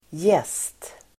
Uttal: [jes:t]